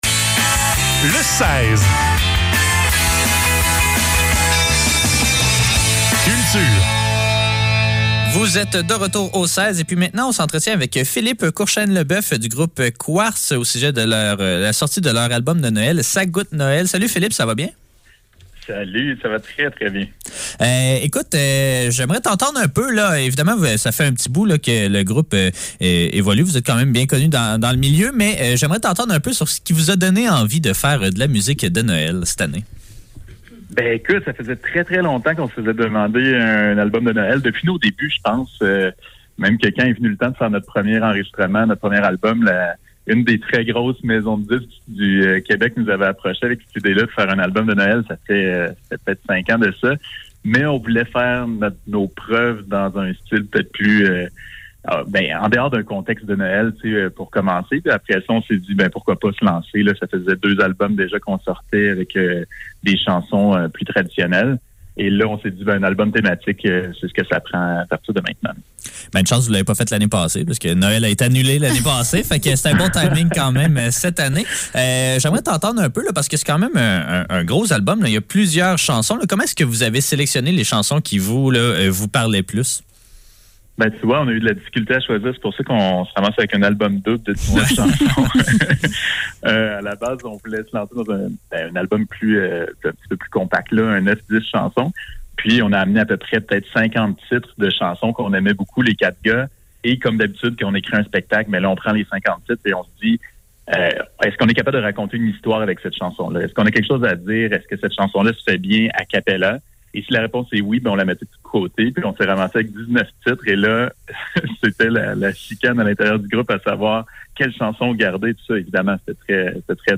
Entrevue-avec-QU4RTZ.mp3